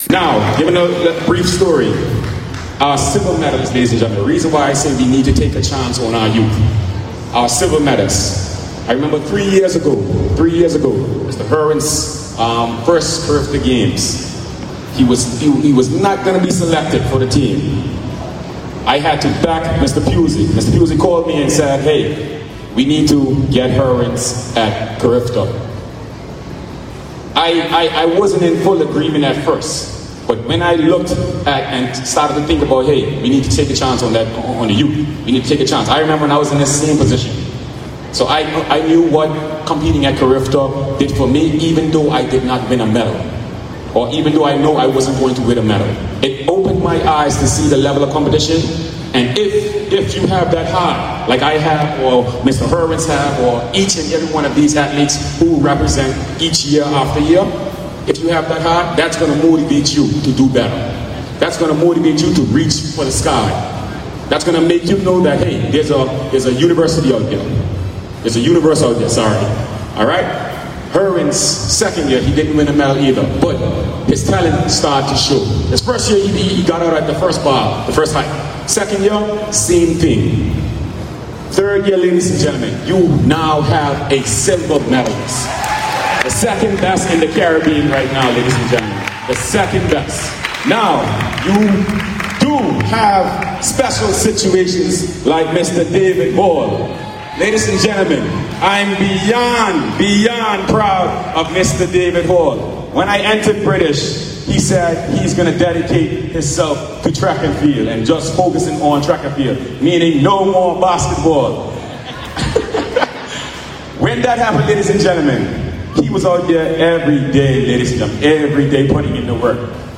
The team returned home yesterday with a fun filled welcome at the Howard Hamilton International Airport, a motorcade followed by a press conference.